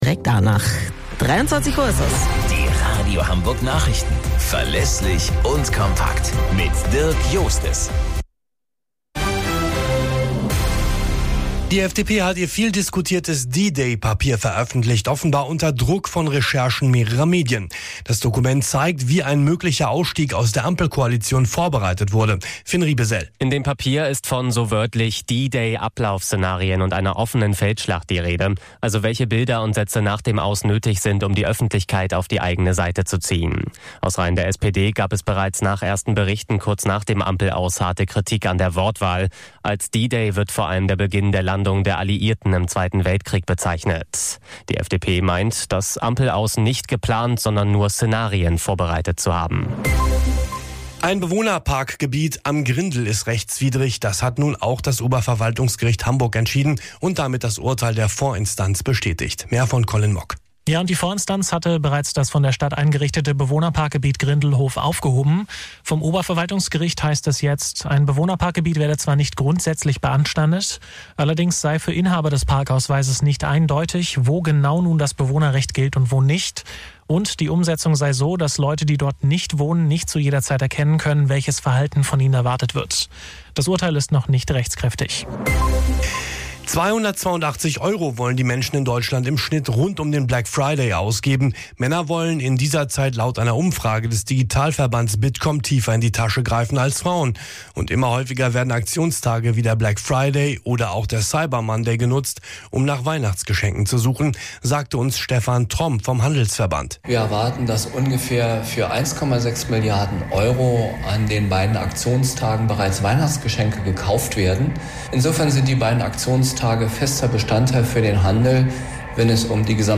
Radio Hamburg Nachrichten vom 29.11.2024 um 05 Uhr - 29.11.2024